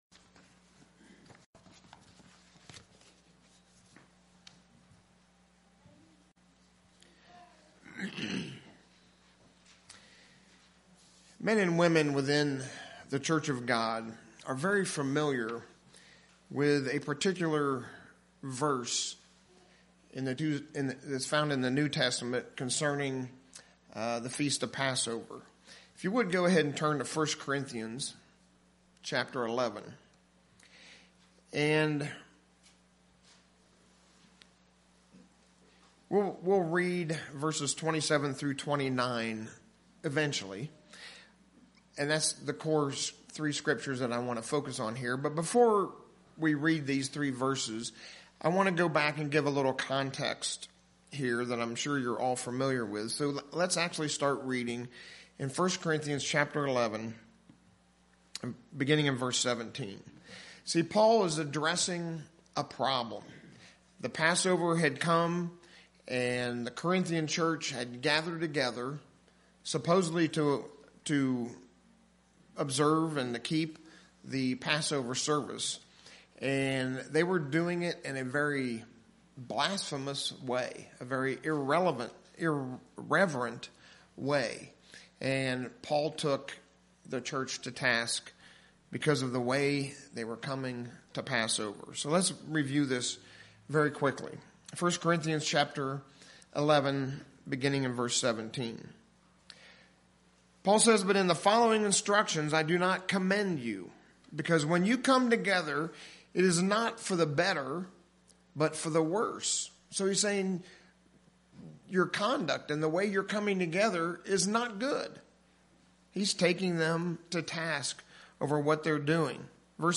As we approach Passover, the Apostle Paul was inspired to remind us that we are to approach the Passover with a proper discernment of our Lord's body and His sufferings. In our sermon today, we will look deeply into Jesus Christ's suffering.